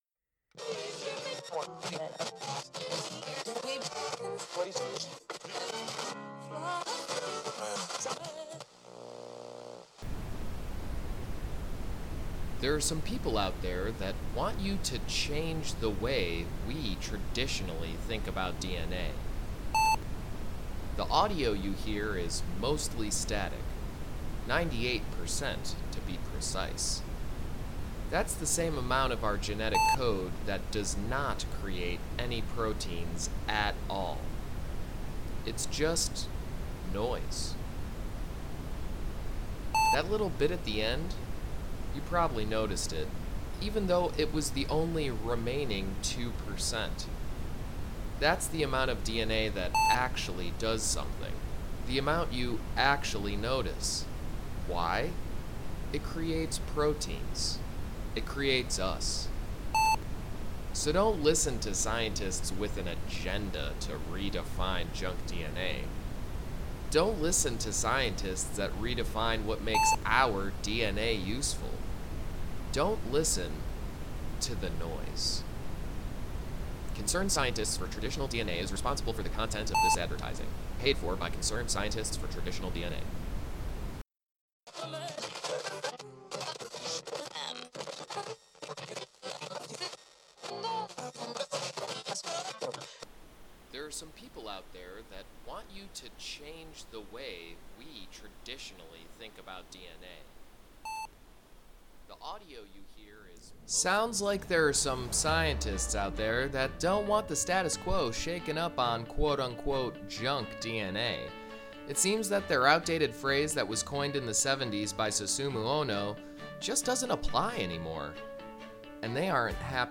So, for our “advertisement” assignment, I made two political attack ads for each side of the debate. Hopefully this can transmit the fundamentals of the junk DNA conversation, but also be indicative of some of the nuances of the conversation.
This piece is a kind of parody, so the location would be “on the radio” inside of it’s own story world.
JunkDNA_AttackAds.mp3